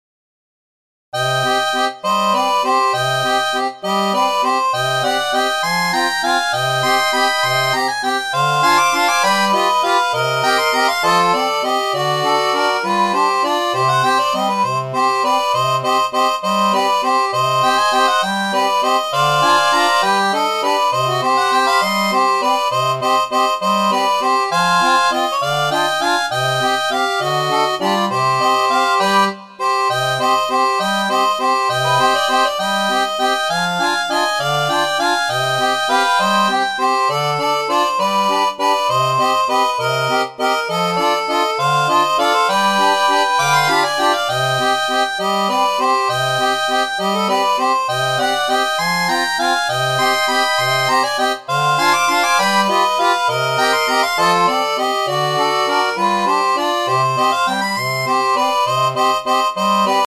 Cm / C